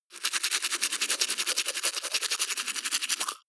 Gemafreie Sounds: Zahnarzt